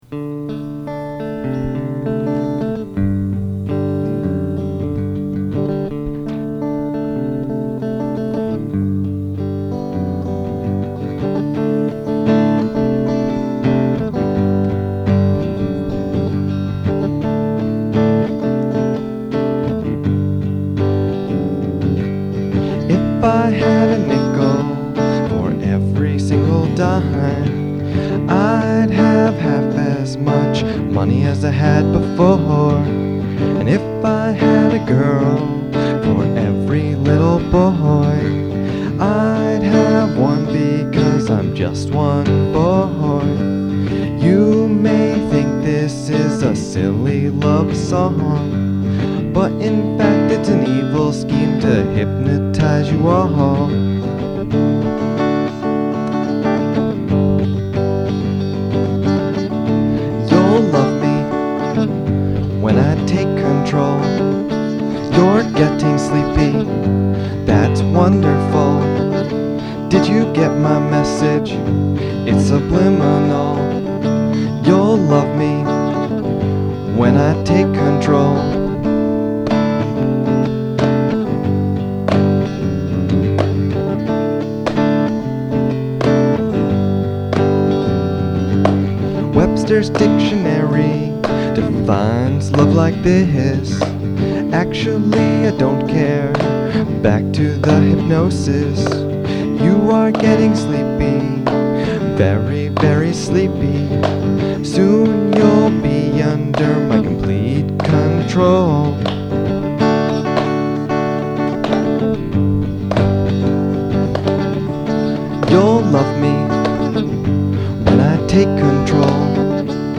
Hypnoses is a break up ballad of sorts.